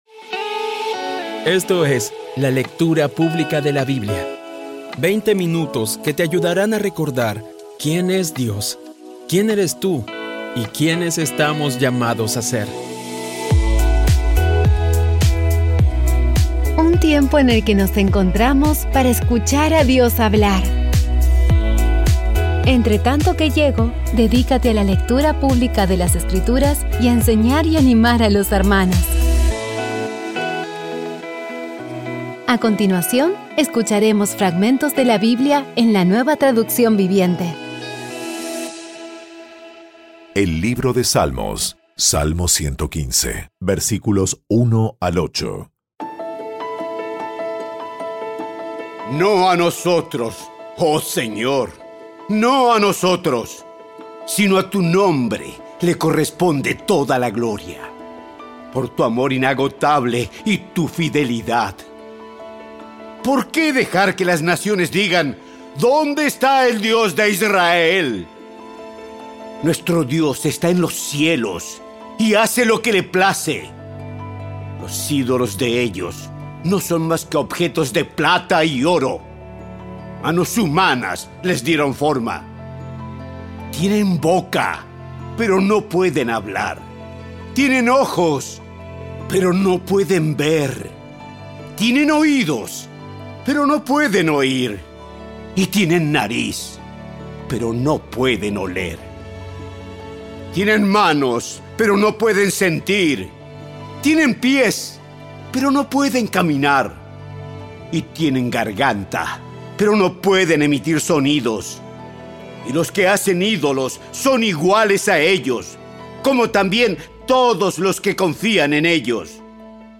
Audio Biblia Dramatizada por CVCLAVOZ / Audio Biblia Dramatizada Episodio 285
Poco a poco y con las maravillosas voces actuadas de los protagonistas vas degustando las palabras de esa guía que Dios nos dio.